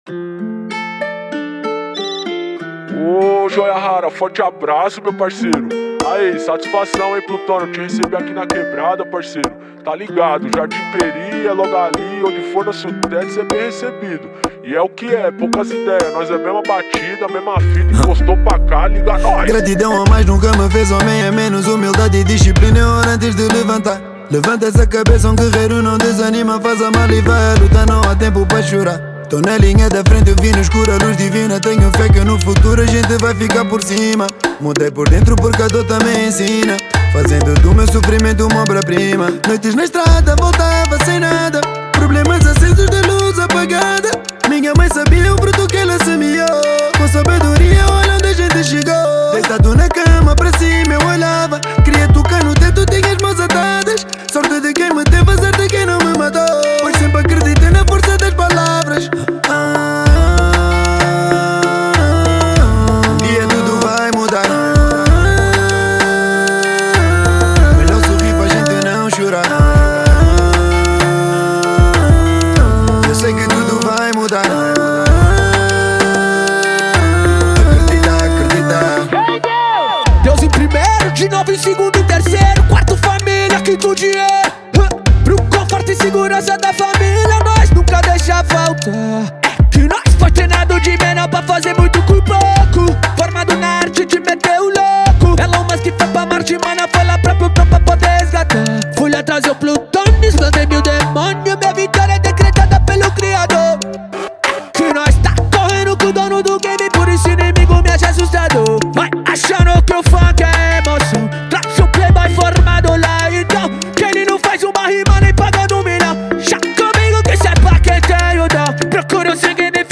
Genero: Funk